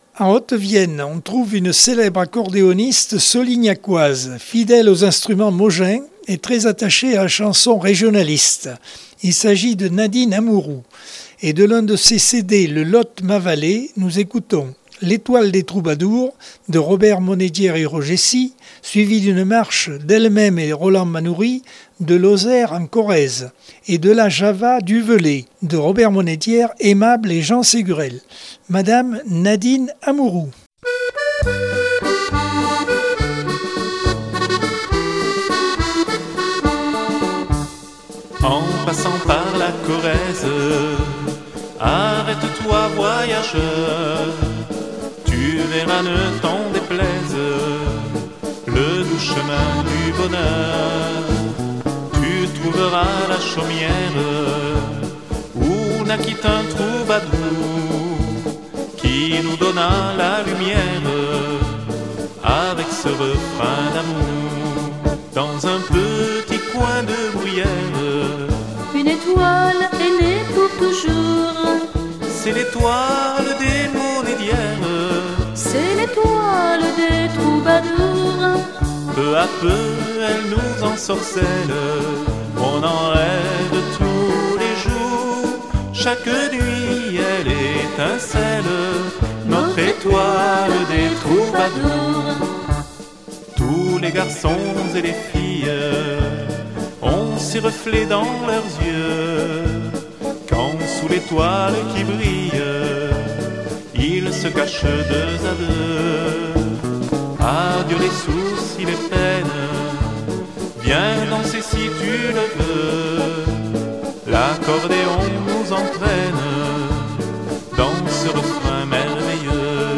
Accordeon 2022 sem 04 bloc 4.